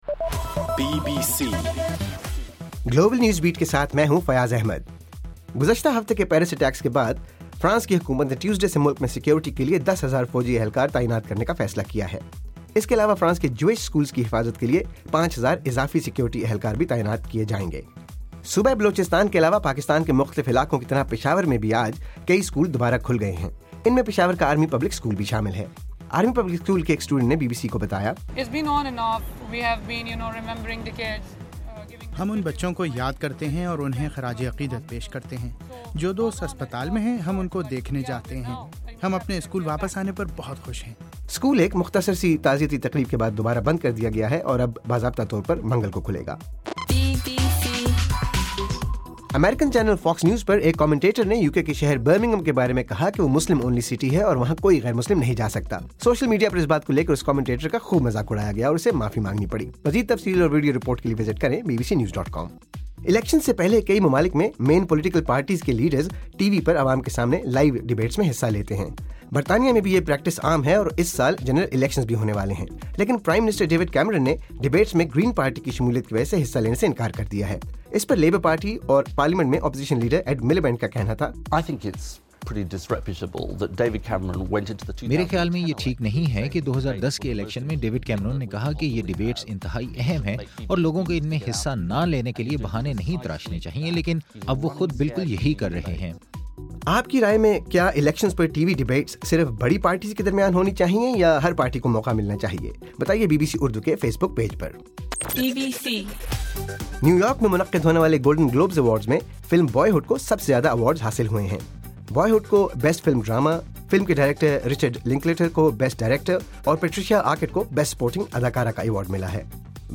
جنوری 12: رات 11 بجے کا گلوبل نیوز بیٹ بُلیٹن